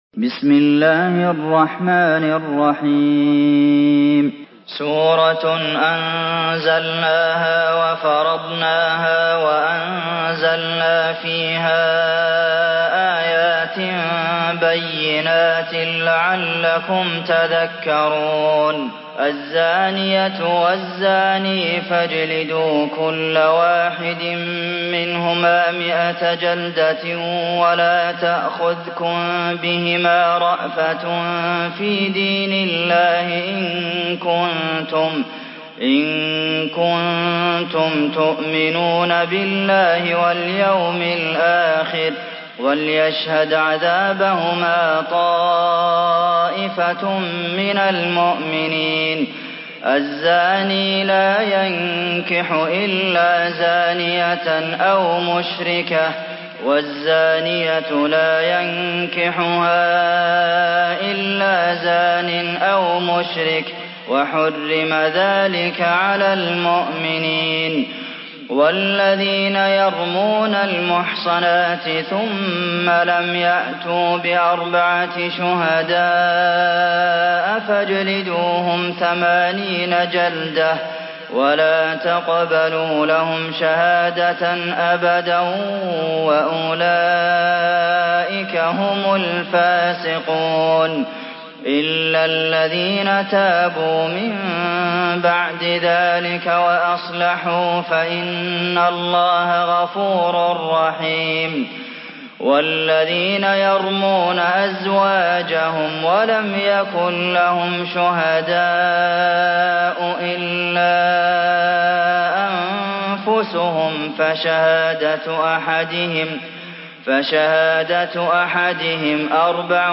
Surah An-Nur MP3 by Abdulmohsen Al Qasim in Hafs An Asim narration.
Murattal Hafs An Asim